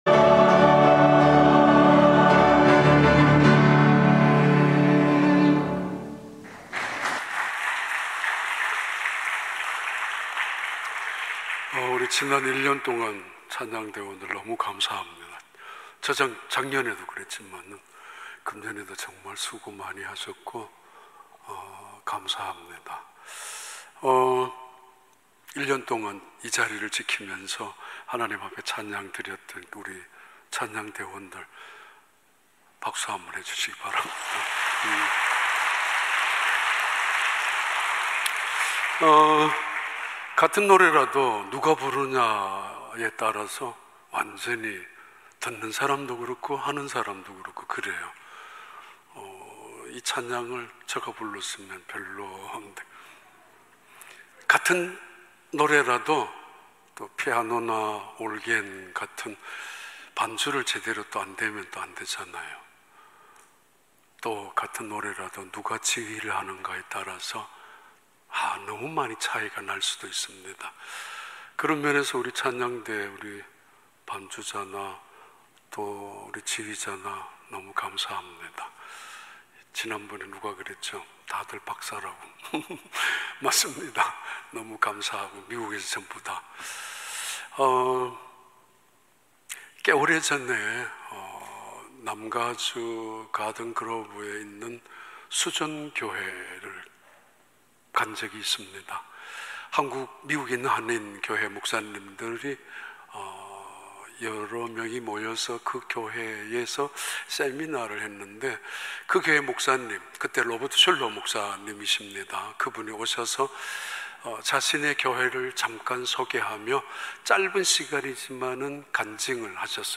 2021년 11월 21일 주일 3부 예배